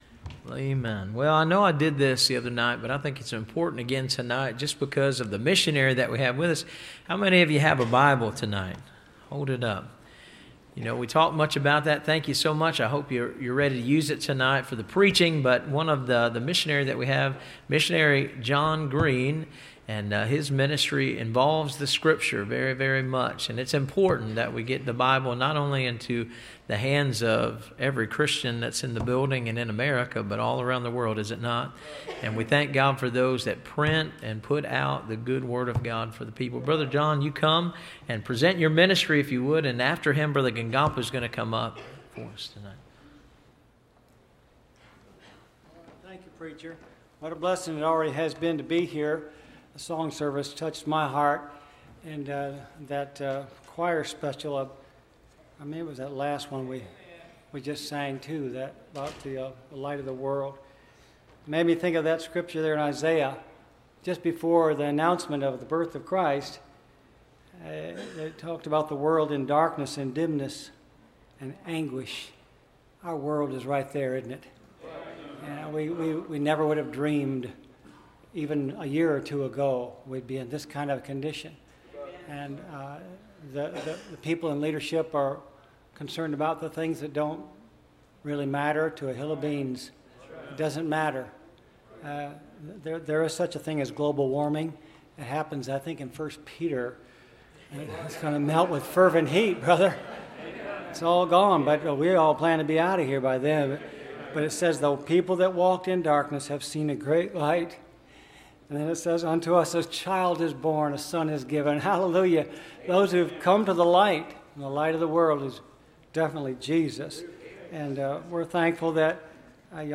Service Type: Missions Conference